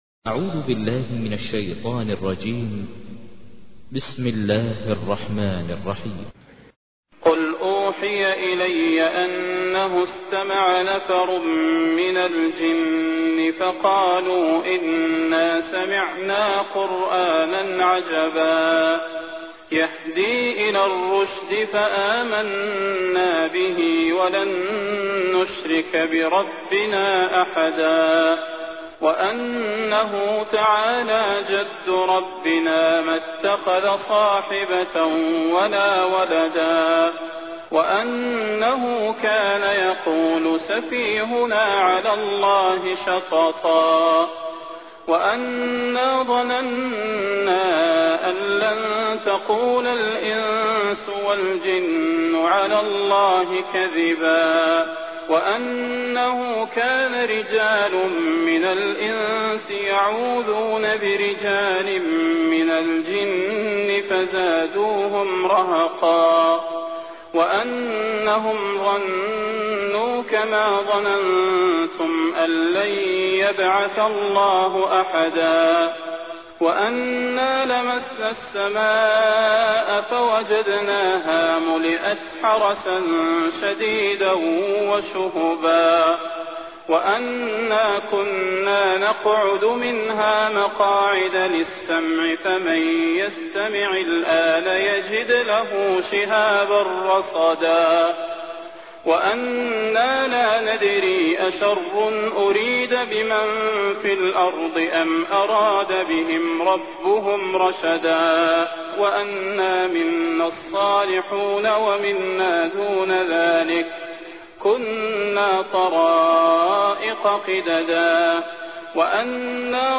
تحميل : 72. سورة الجن / القارئ ماهر المعيقلي / القرآن الكريم / موقع يا حسين